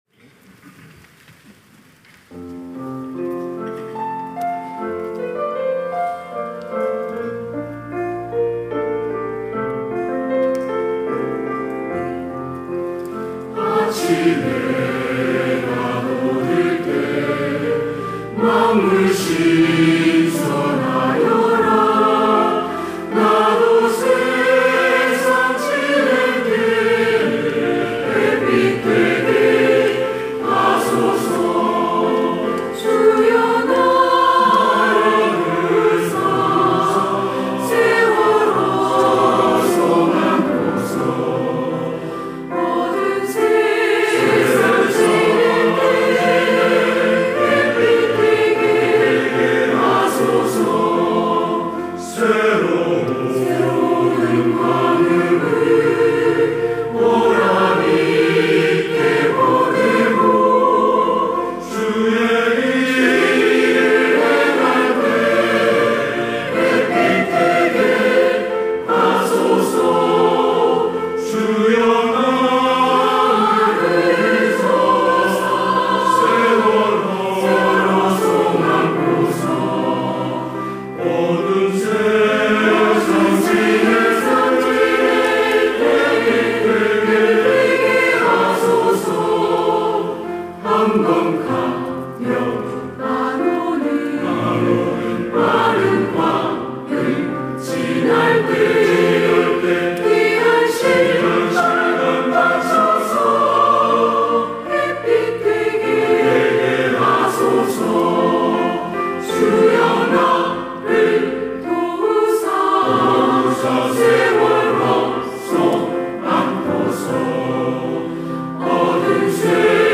시온(주일1부) - 아침 해가 돋을 때
찬양대